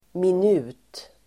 Uttal: [min'u:t]